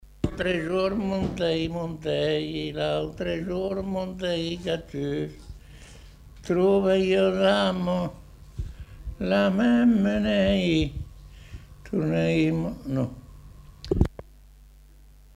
Aire culturelle : Savès
Genre : chant
Effectif : 1
Type de voix : voix d'homme
Production du son : chanté
Ecouter-voir : archives sonores en ligne